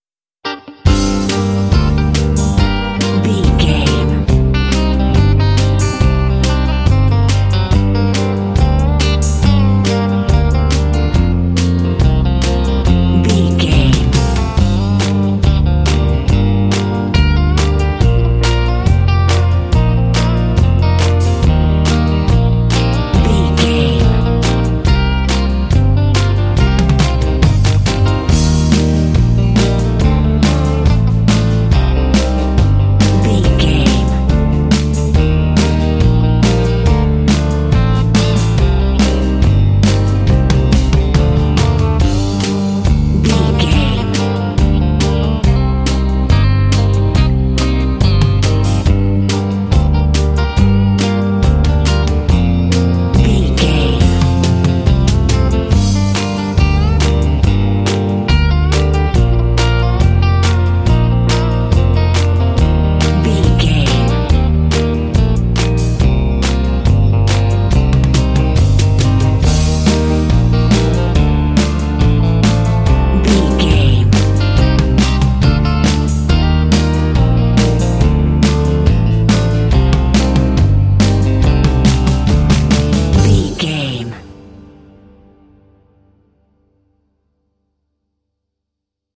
Aeolian/Minor
smooth
calm
groovy
drums
electric guitar
bass guitar
piano
contemporary underscore
country